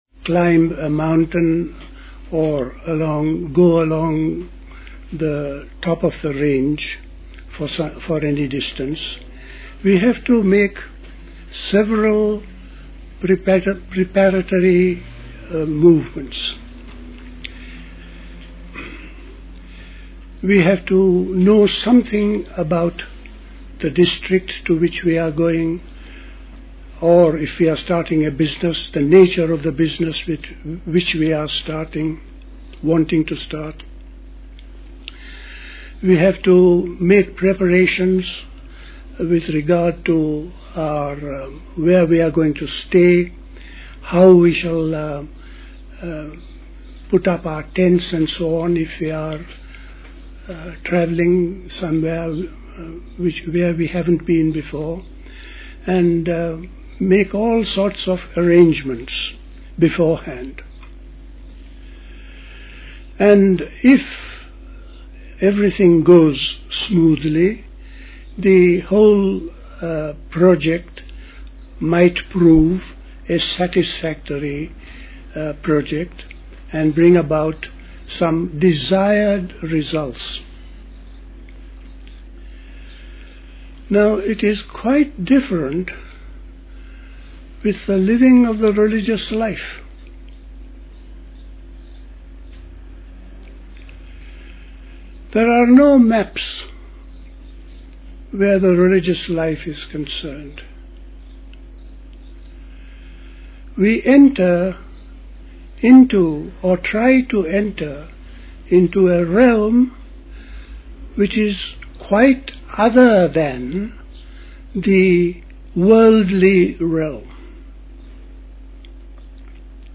at Dilkusha, Forest Hill, London on 2nd July 1983